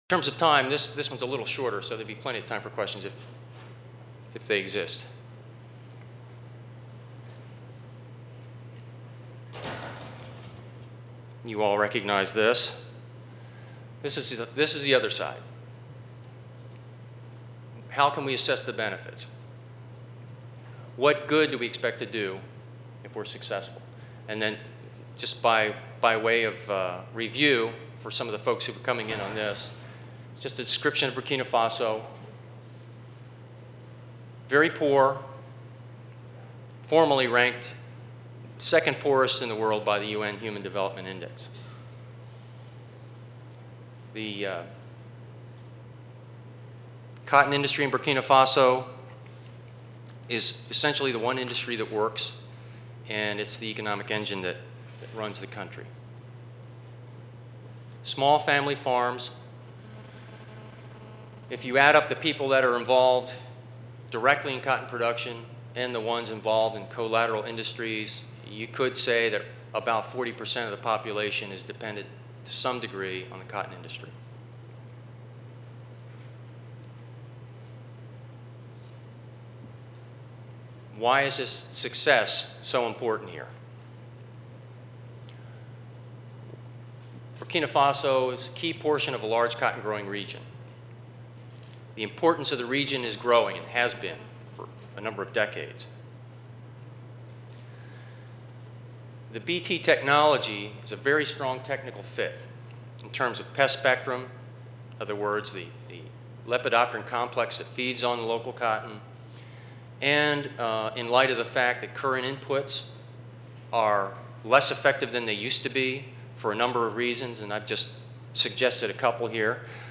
INERA Audio File Recorded presentation